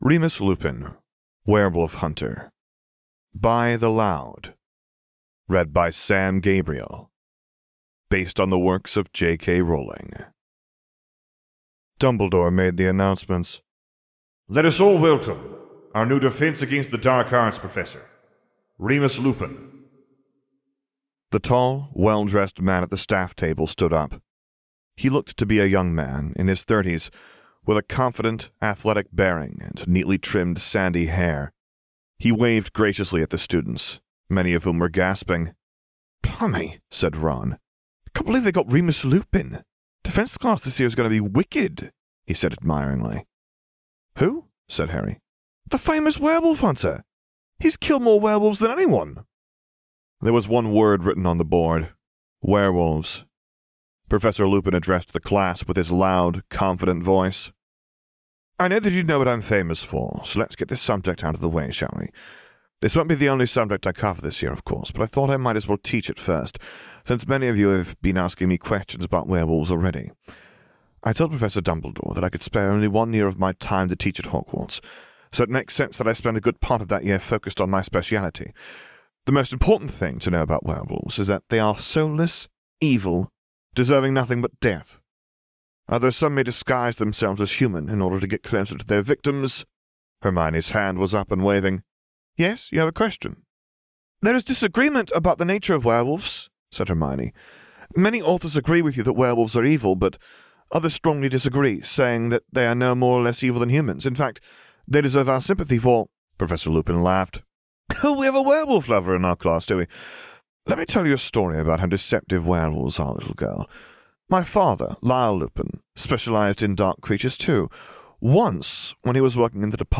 Audiobook | Archive of Our Own
Podfic